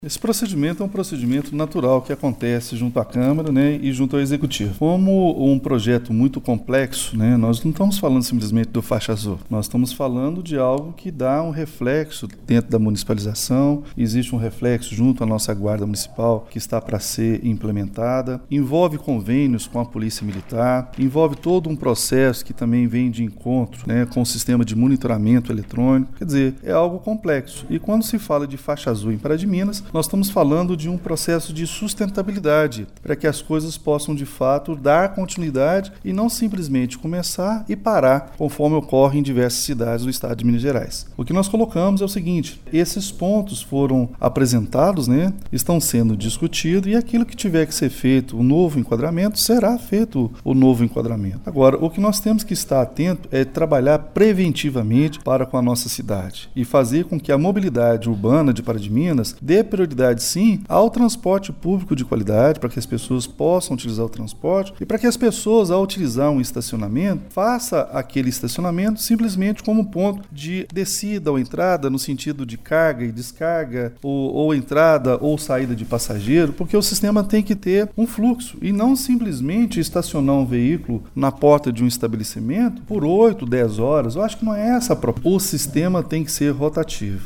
Nesta quarta-feira, 29 de agosto, o prefeito Elias Diniz abordou novamente o assunto. Ele voltou a dizer que o projeto é amplo e envolve todos os aspectos da mobilidade urbana de forma sustentável: